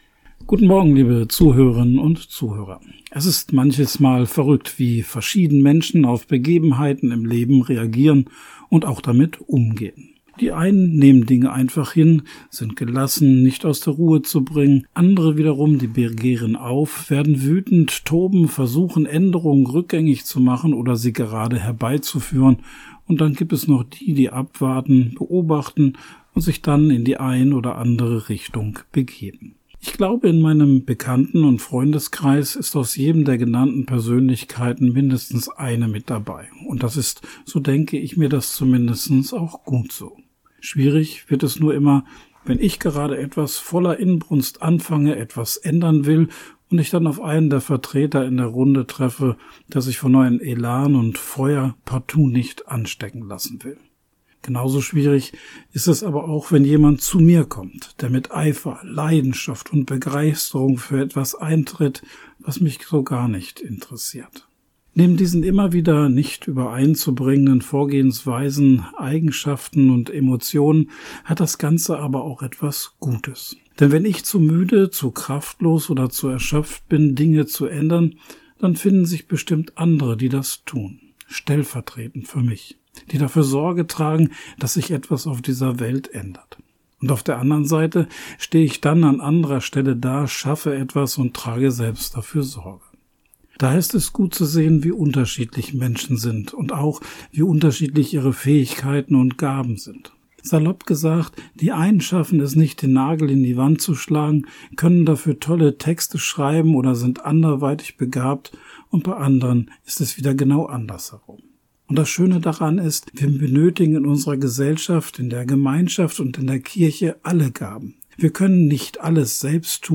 Radioandacht vom 24. Januar